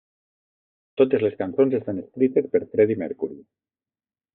Pronounced as (IPA) [əsˈkɾi.təs]